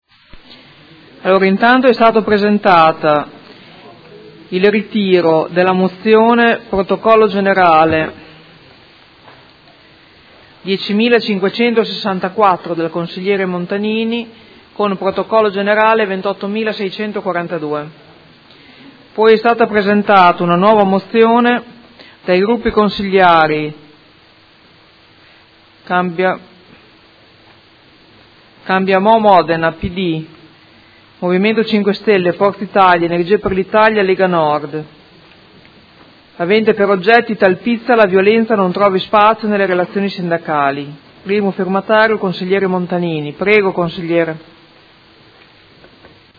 Presidente — Sito Audio Consiglio Comunale
Presidente